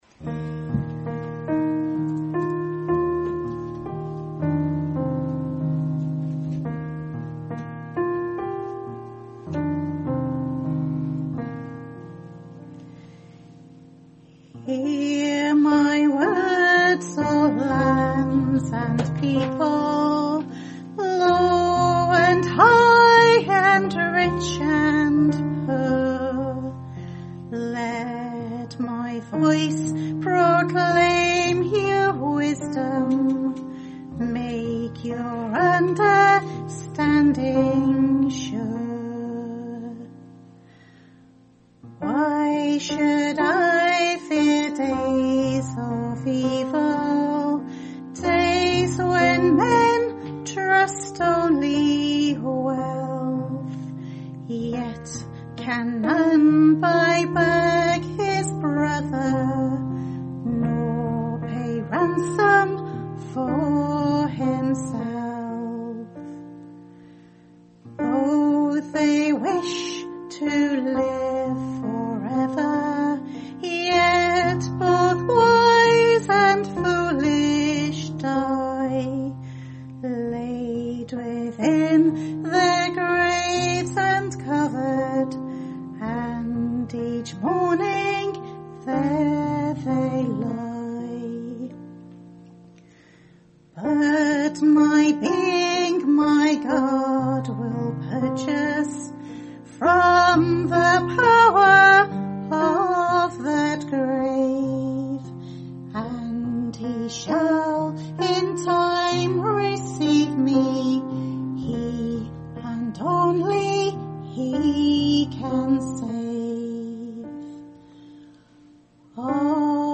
Special Music
sung Wales UK UB 6 Apr 2018